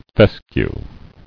[fes·cue]